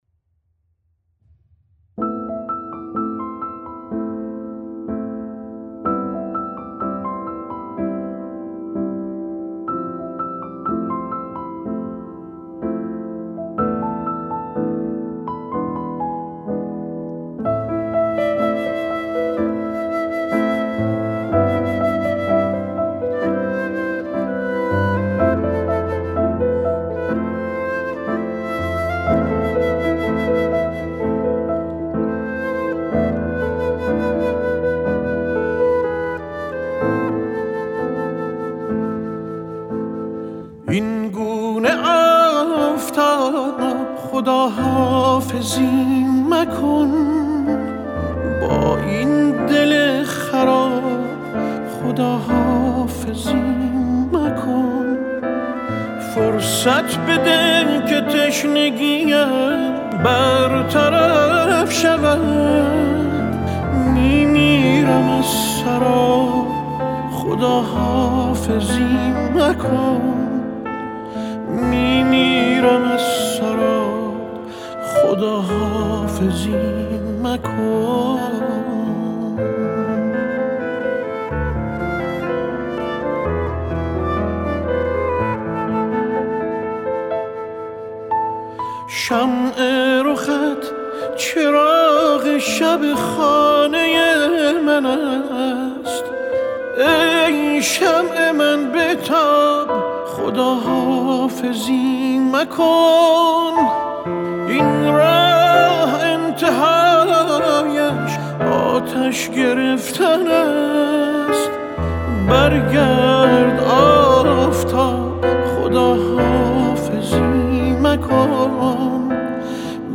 ژانر: سنتی